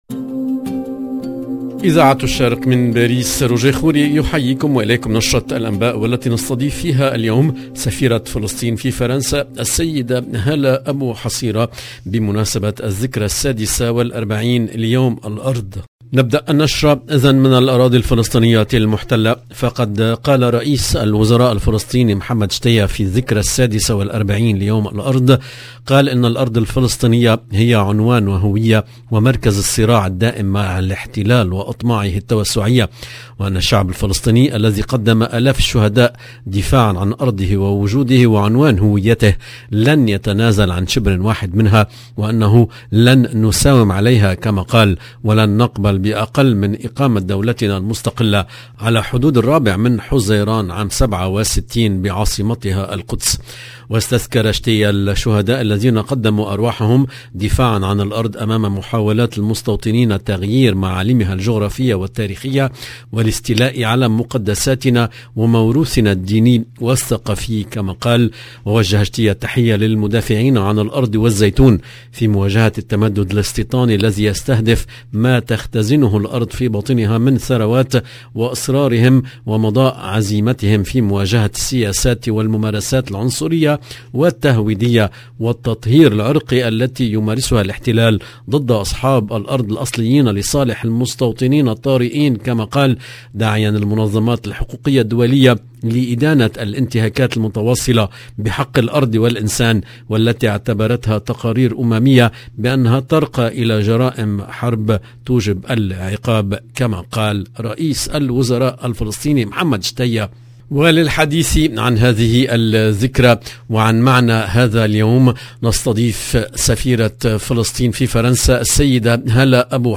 LE JOURNAL DU SOIR EN LANGUE ARABE DU 30/03/22